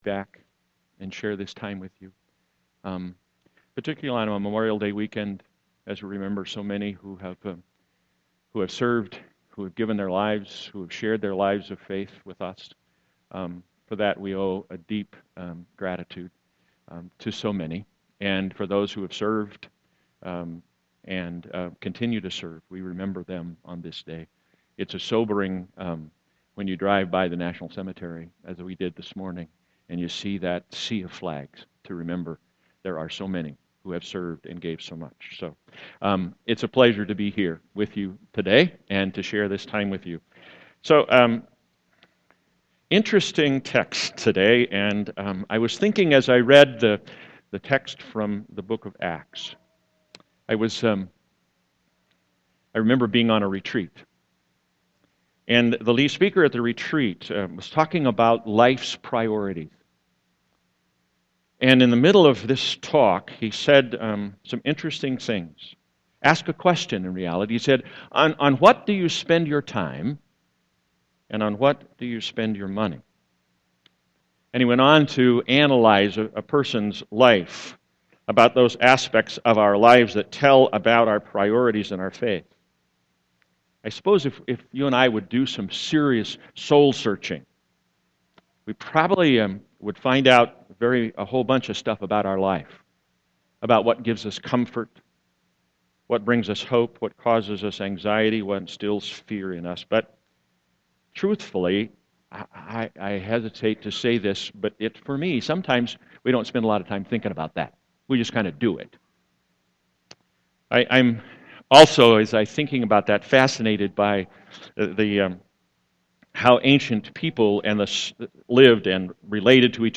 Sermon 5.25.2014